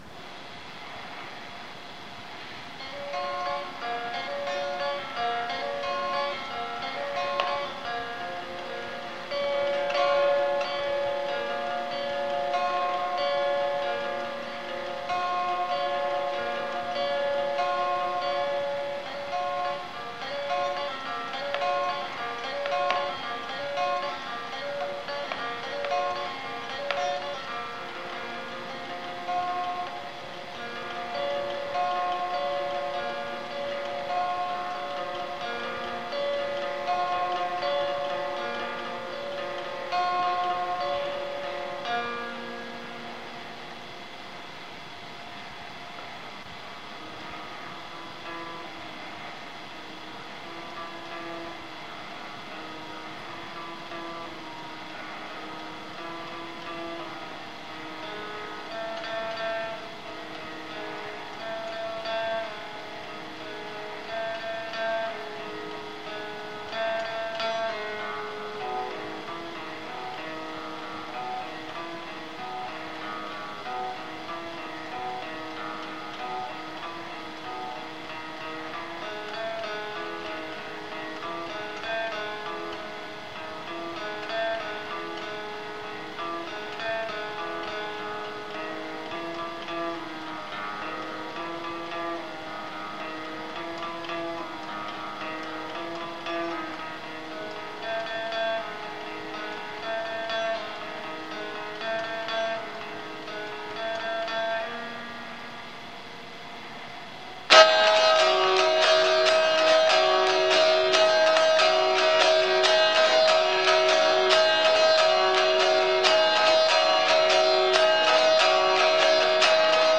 But this is one of my favorites, because of the ambience of the location. I recorded it in a stairwell in the college dorm of my junior year, upstairs from the basement station where I hosted my own weekly radio show. At a perfect moment during a lull in the middle of my song, a burst of students came into the stairwell, and my playing can be heard to continue as the noise recedes.